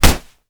kick_hard_impact_05.wav